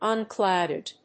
発音記号
• / `ʌnklάʊdɪd(米国英語)